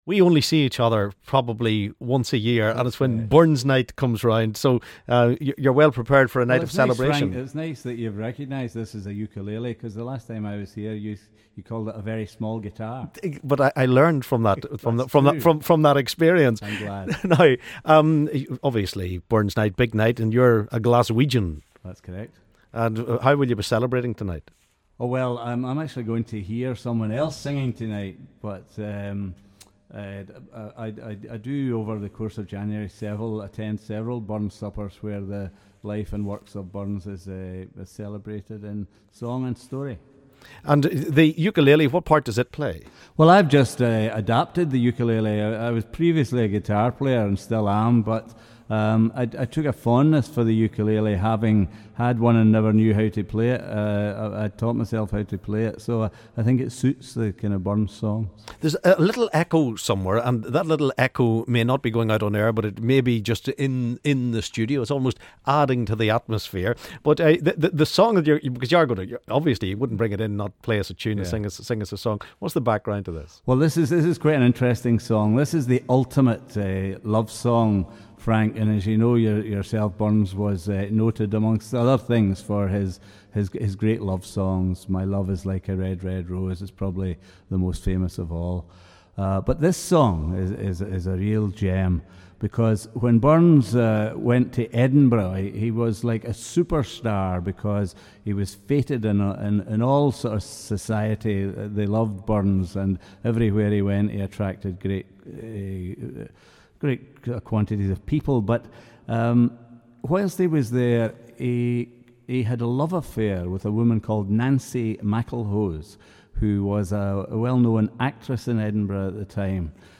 singing in the studio